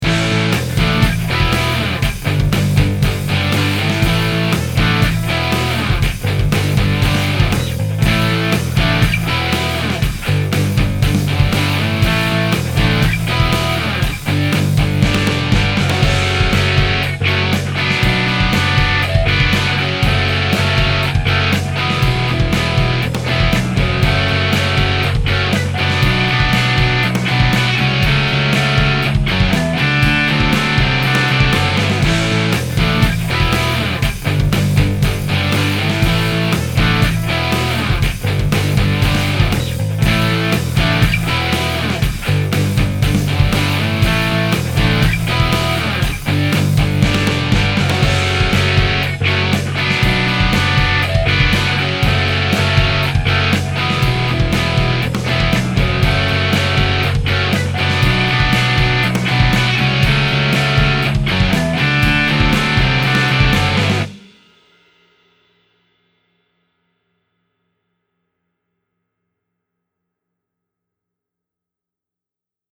AMP 1 : non-delayed in L, 30ms delayed in R (-2dB)
AMP 2 : non-delayed in R, 20ms delayed in L (-2dB)
Parallel Haas:
That is a nice even guitar sound that has a super-wide stereo field.